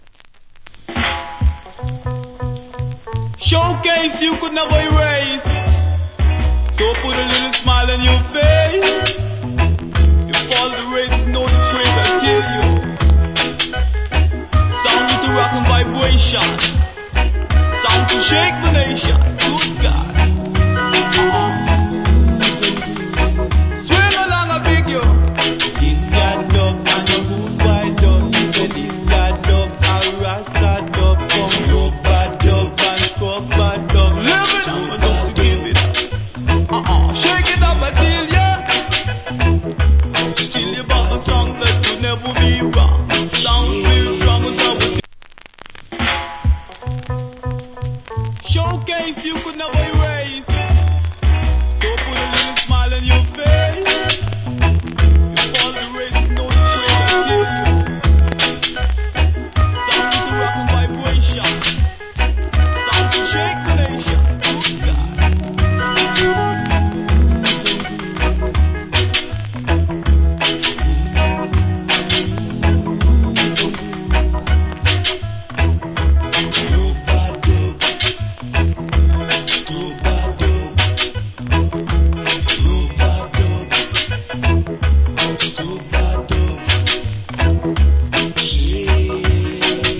コメント Wicked DJ.
Good Dub.